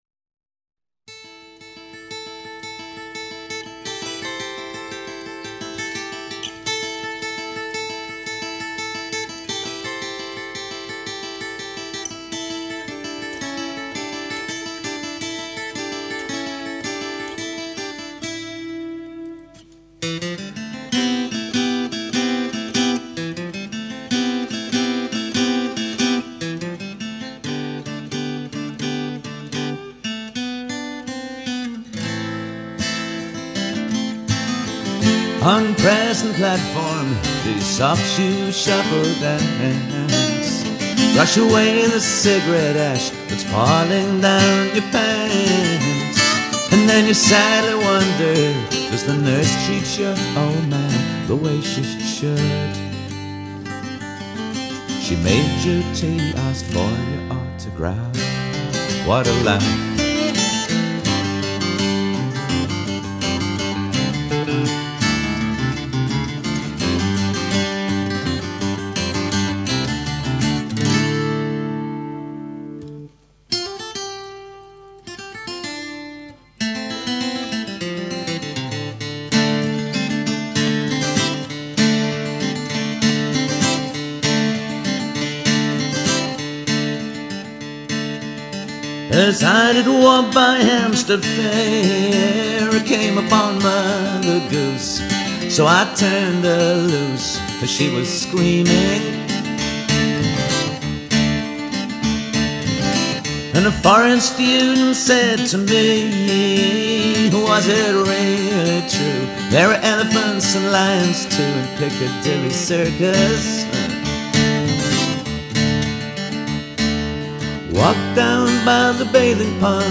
acoustic medley